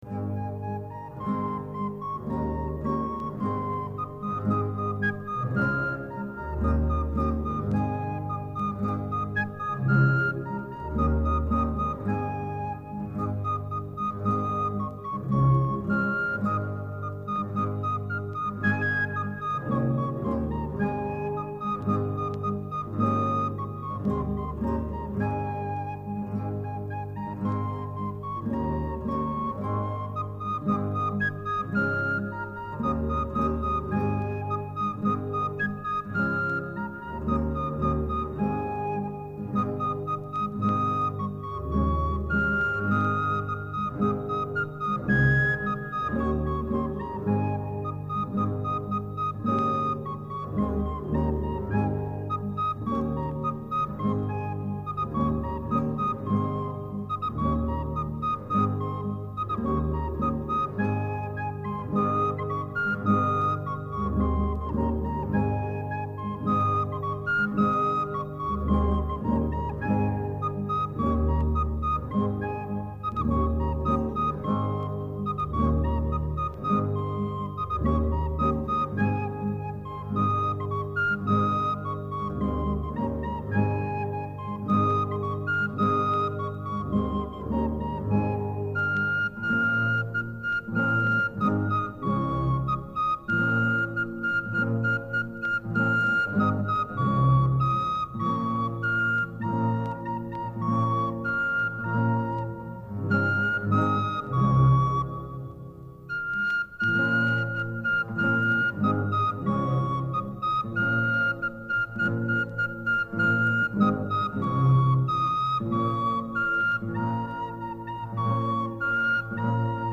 danza_santa_orosia.mp3